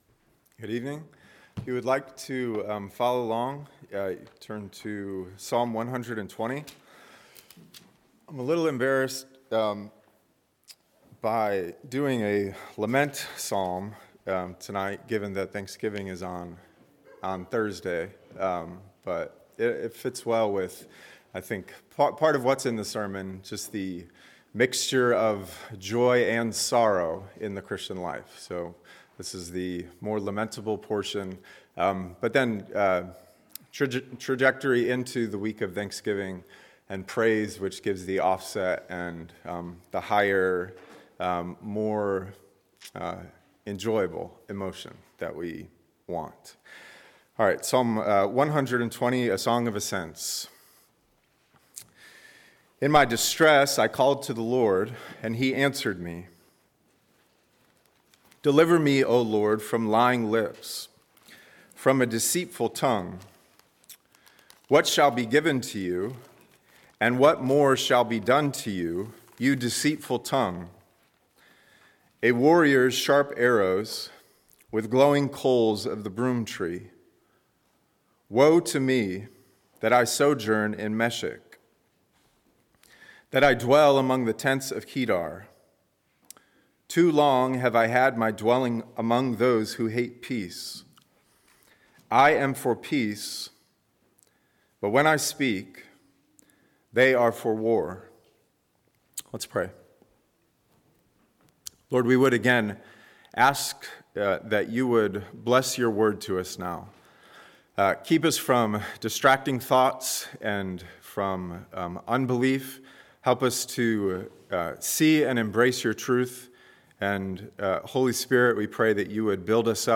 A message from the series "Psalms."